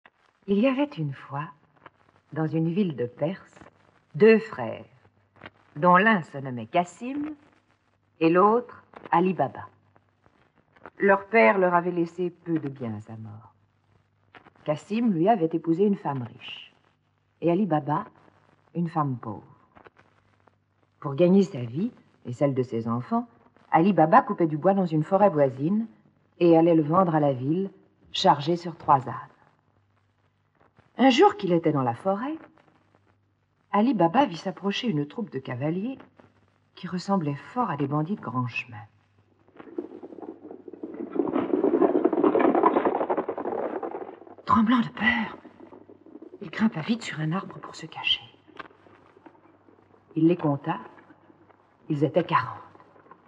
Lu par Françoise Christophe thèmes musicaux
enregistrement original de 1958